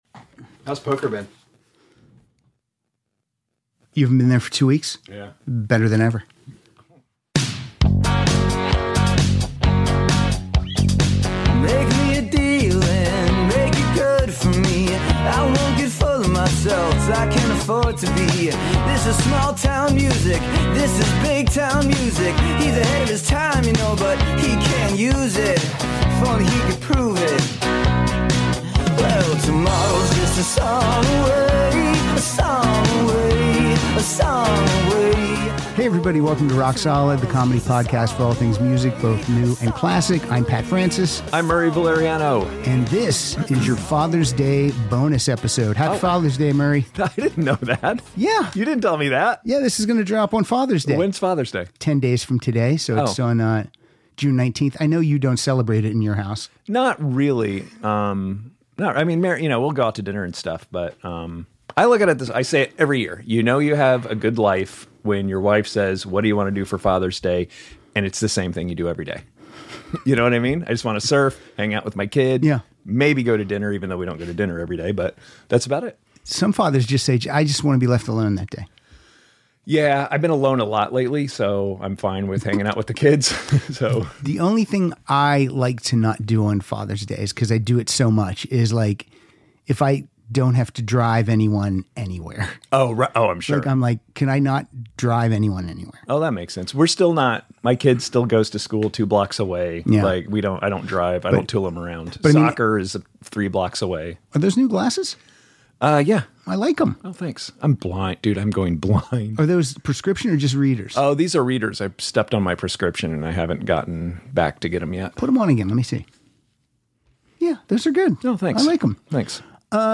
cracking wise and playing some deep cuts from the Rock N Roll Hall Of Fame Class Of 2022.